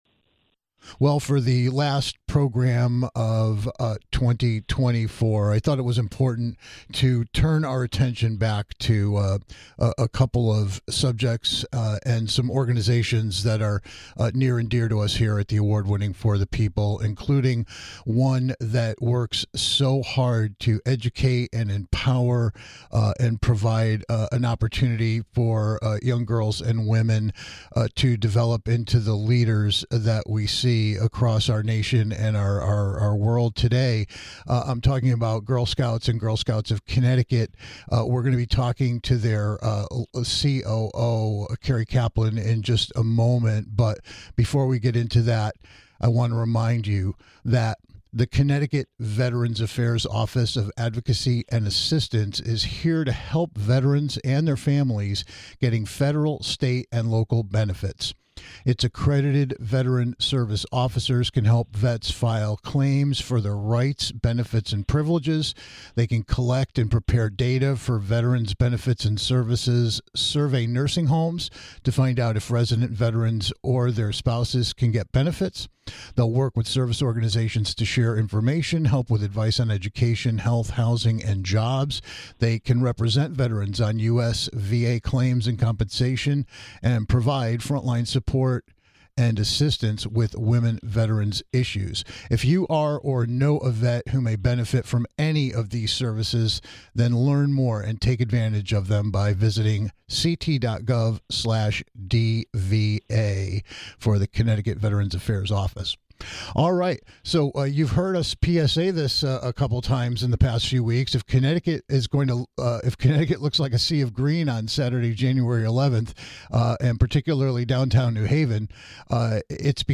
If you know - or are the parent or caregiver of a young woman - and you want to help expose them to a world of opportunities, then tune in to hear our latest conversation with one of the top representatives from the Girl Scouts of Connecticut.